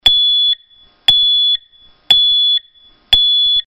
ZUMBADOR CON 8 SONIDOS
Zumbador Electrónico Empotrable para cuadro Ø 22,5MM
dB 86-100